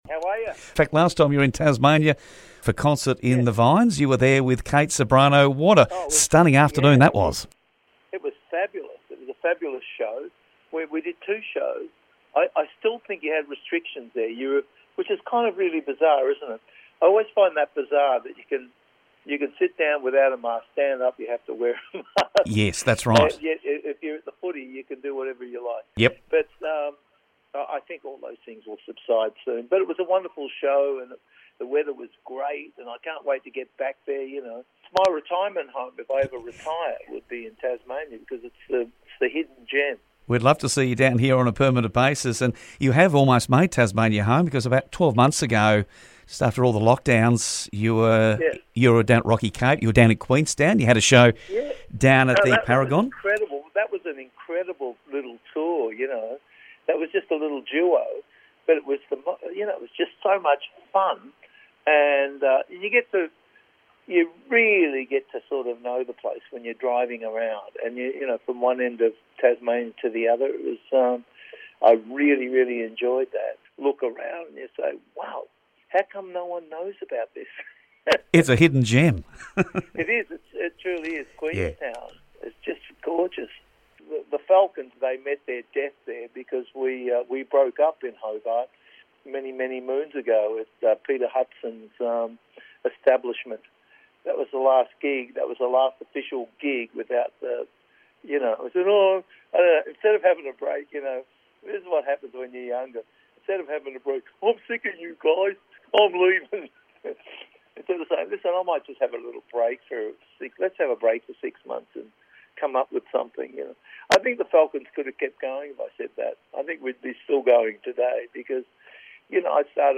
Joe Camilleri and The Black Sorrows return to Tassie for 4 shows and had a chat ahead of the show at Rocky Cape Tavern on Friday April 1.